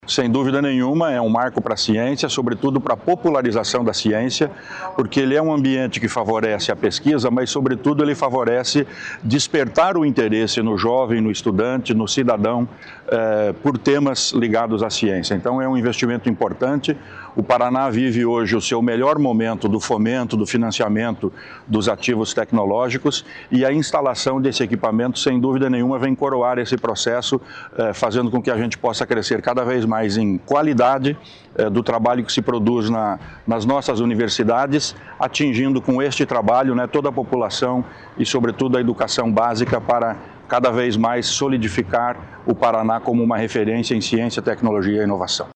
Sonora do secretário Estadual da Ciência, Tecnologia e Ensino Superior, Aldo Bona, sobre a assinatura da parceria com empresa alemã Carl Zeiss